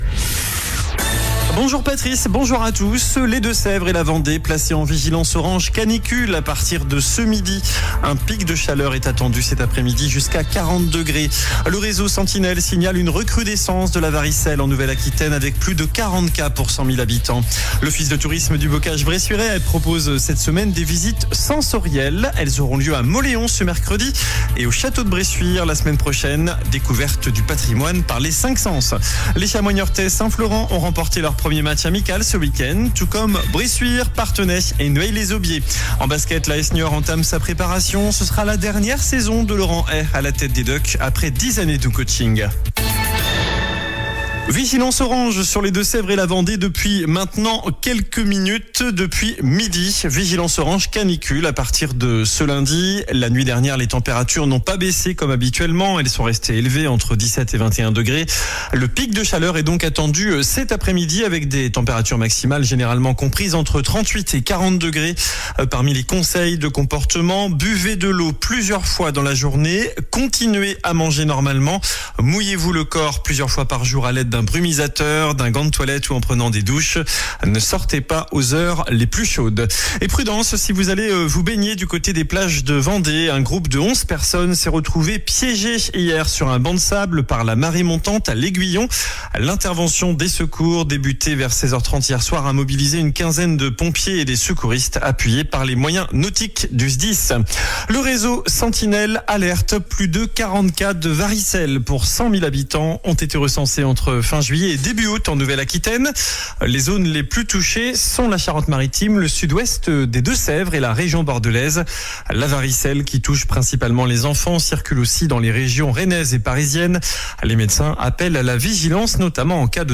JOURNAL DU LUNDI 11 AOÛT ( MIDI )